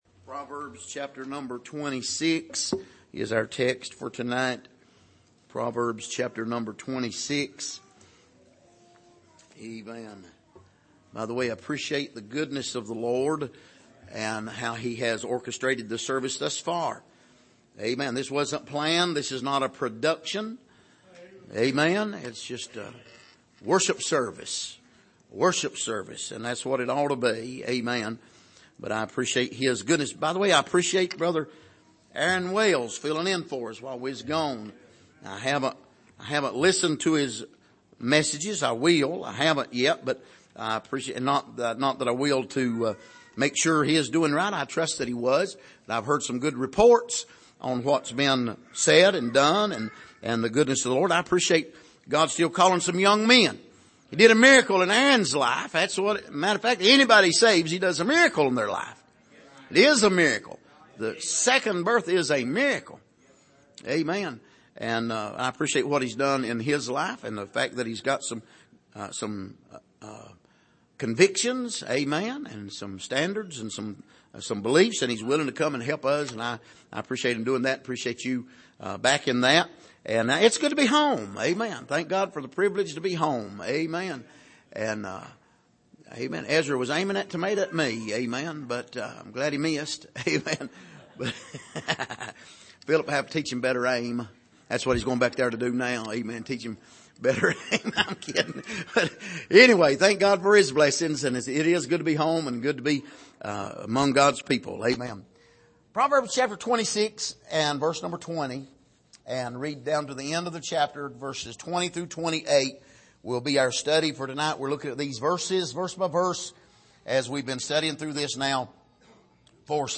Passage: Proverbs 26:20-28 Service: Sunday Evening